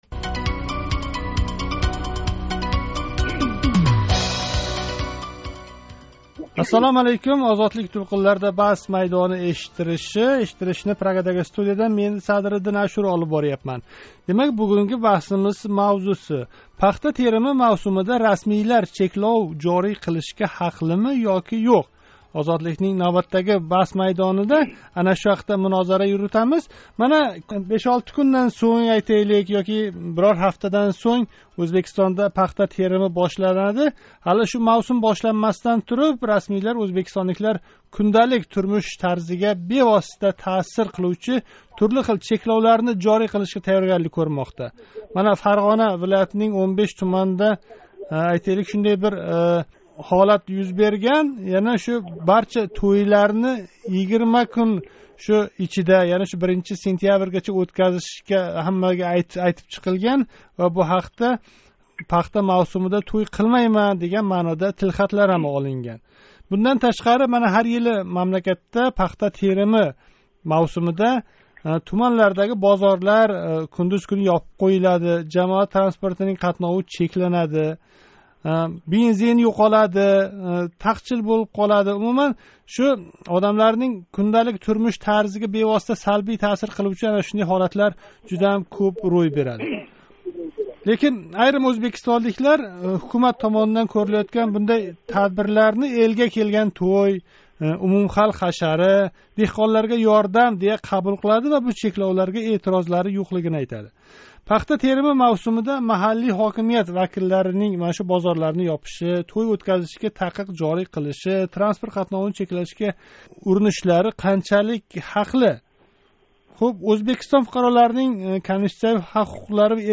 Озодликнинг навбатдаги Баҳс майдонида Ўзбекистонда яқинда бошланадиган пахта терими мавсумида расмийлар турли чекловларни жорий қилишга қанчалик ҳақли экани тўғрисида мунозара юритилди.